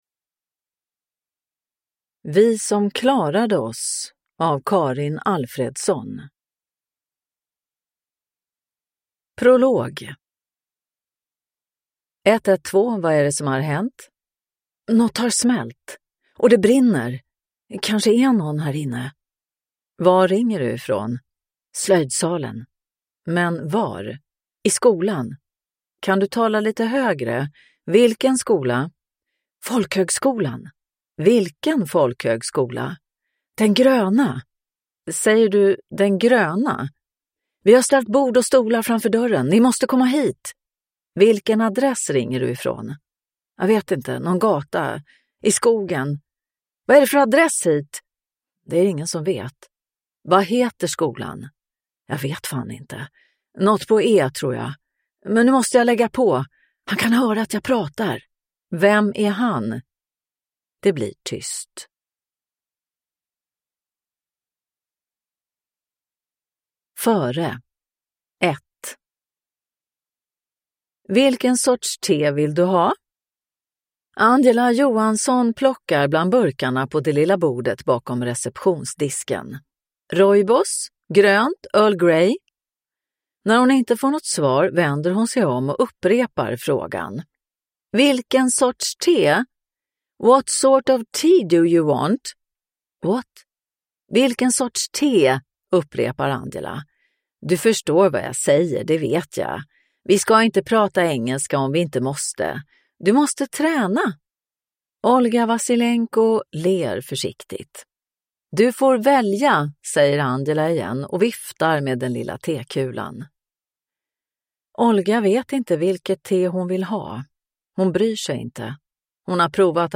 Vi som klarade oss – Ljudbok